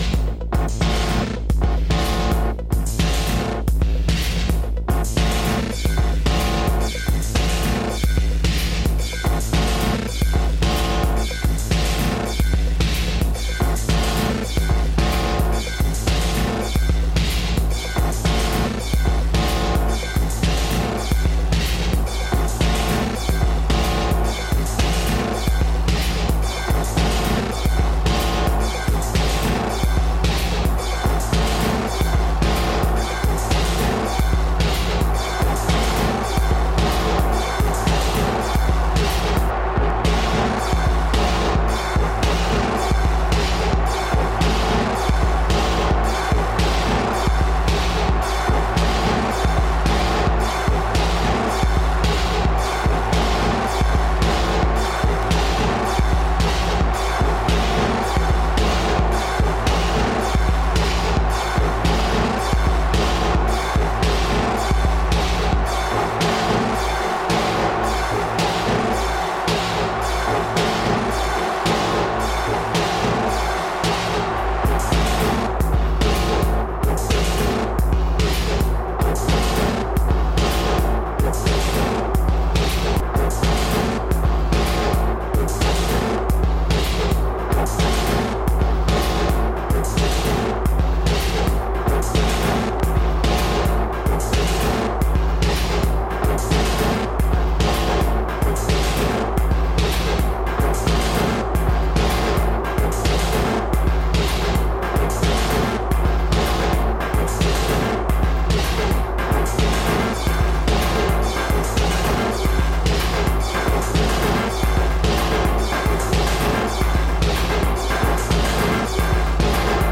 EBM/Industrial